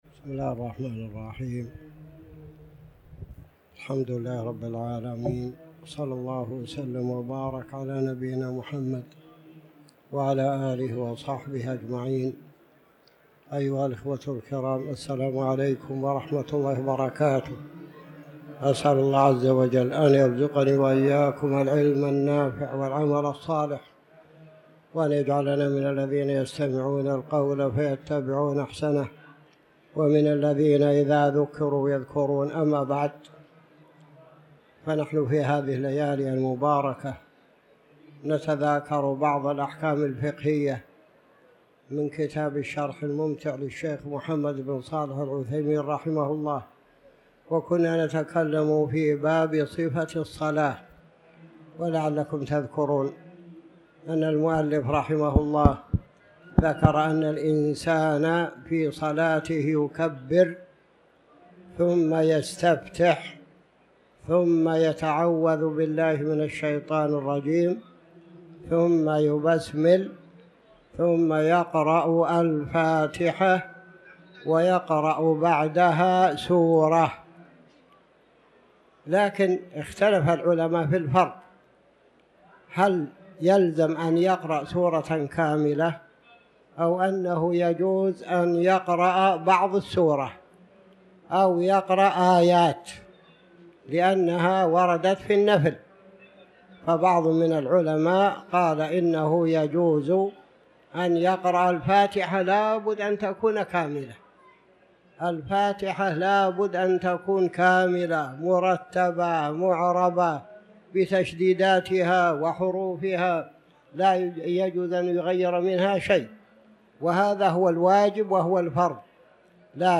تاريخ النشر ٥ ذو القعدة ١٤٤٠ هـ المكان: المسجد الحرام الشيخ